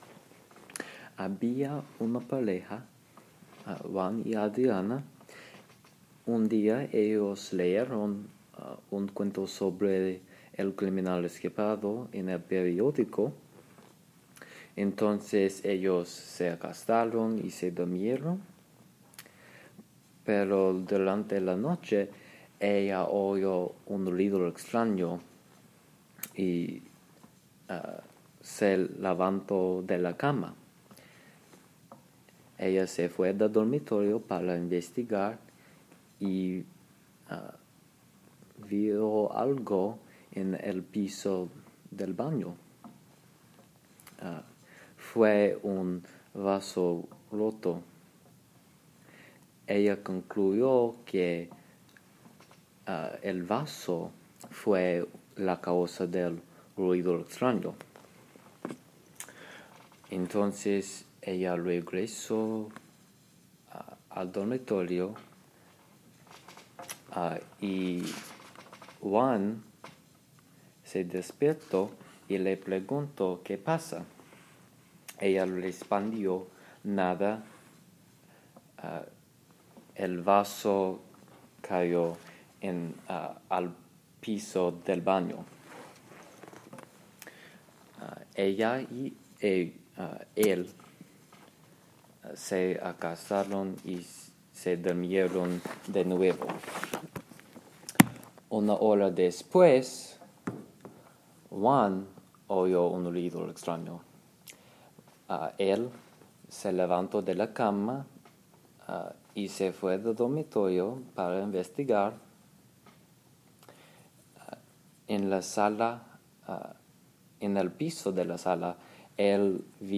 ruido en la noche recording